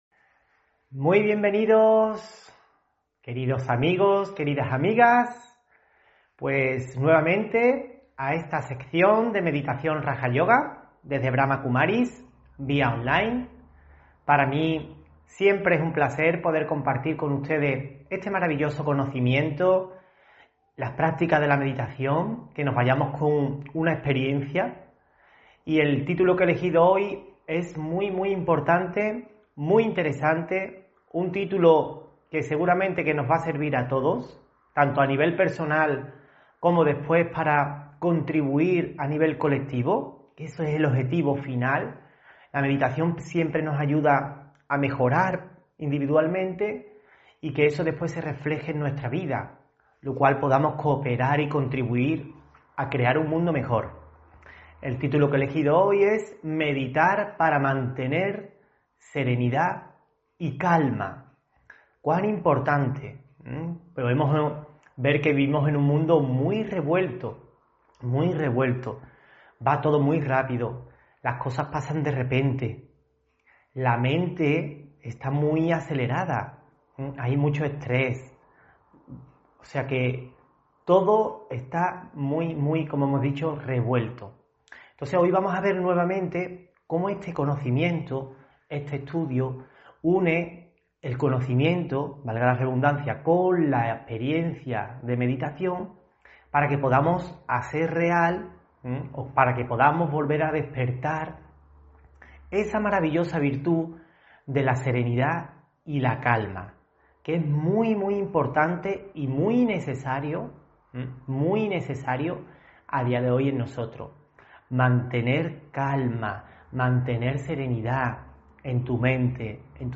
Meditación y conferencia: Meditar para mantener serenidad y calma (16 Febrero 2022)